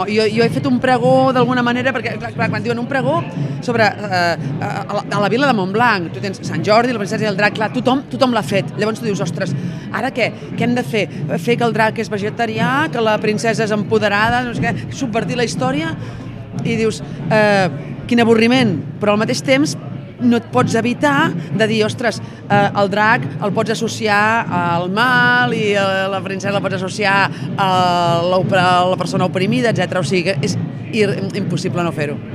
ÀUDIO: Empar Moliner ha destacat que el pregó no podia estar al marge de l’actualitat
Empar-Moliner-prego-Setmana-Medieval.mp3